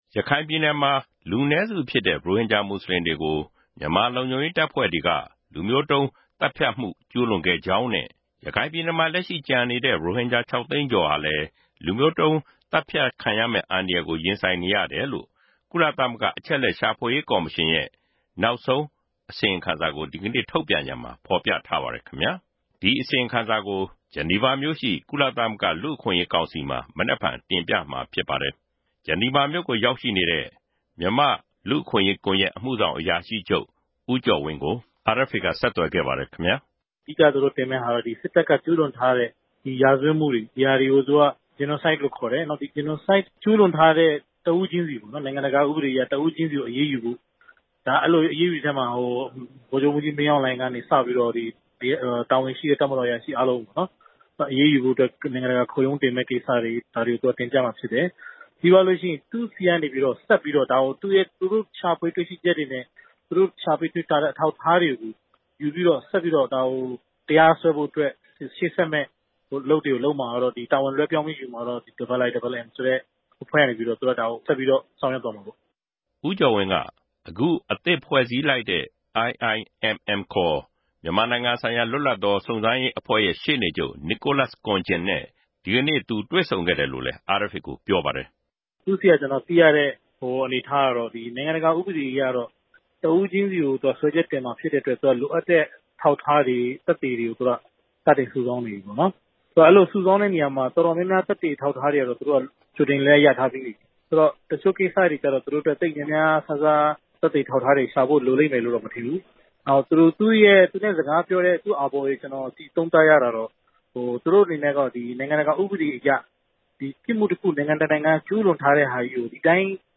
ဆက်သွယ်မေးမြန်းတင်ပြထားပါတယ်။